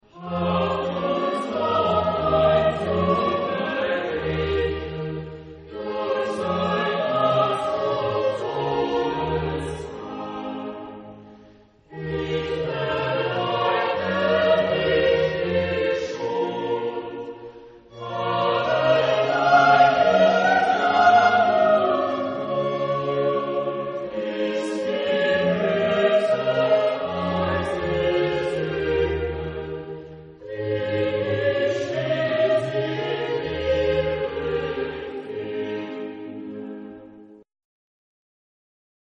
Género/Estilo/Forma: Sagrado ; Barroco ; Coral
Tipo de formación coral: SATB  (4 voces Coro mixto )